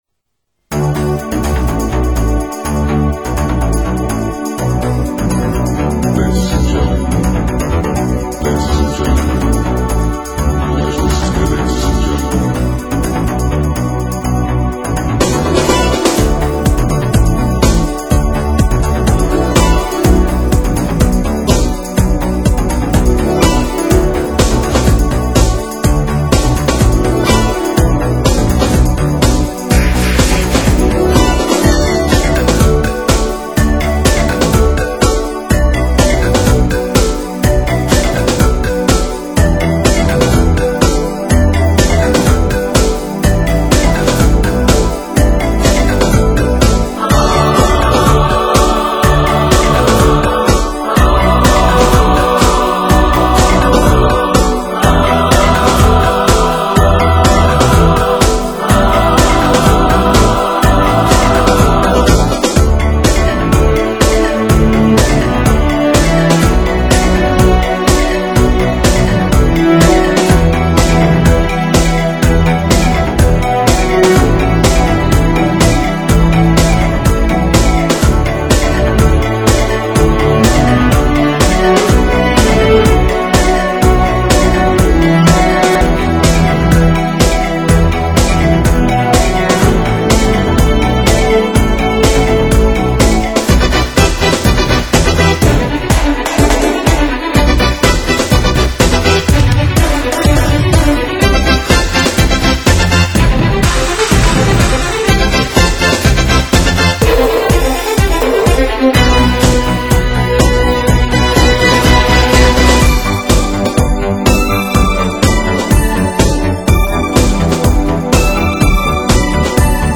专辑语言：纯音乐
集结多位国际音乐高手，全新演绎时代经典名曲，动态无比，极具震撼力。
音乐不错，音质欠佳！